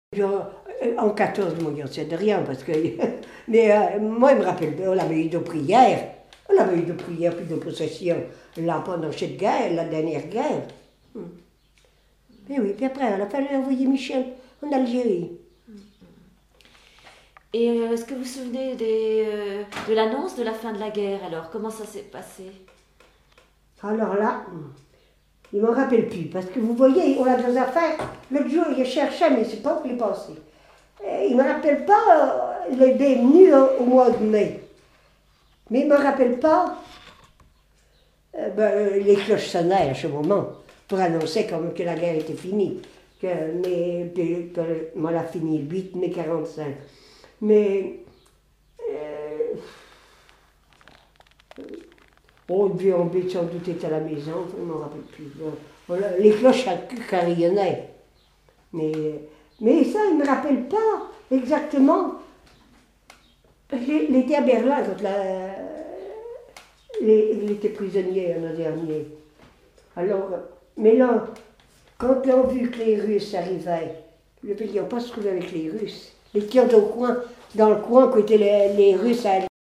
Témoignage sur la vie de l'interviewé(e)
Catégorie Témoignage